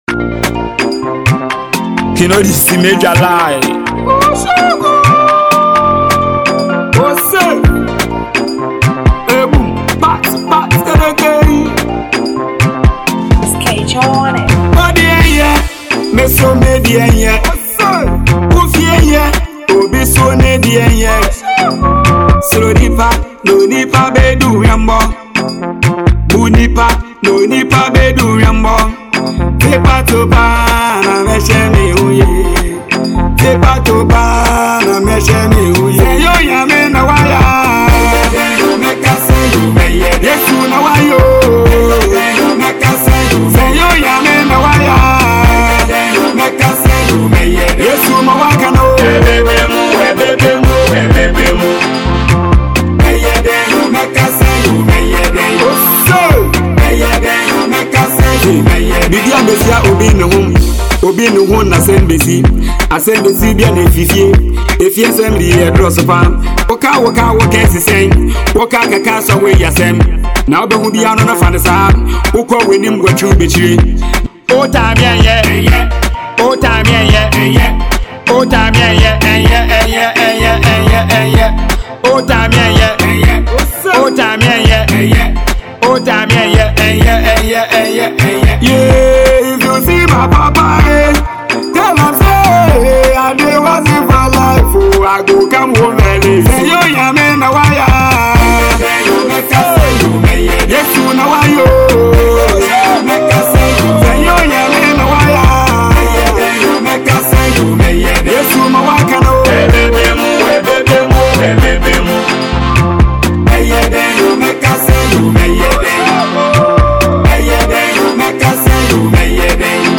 Ghana Music
New tune from dance music sensation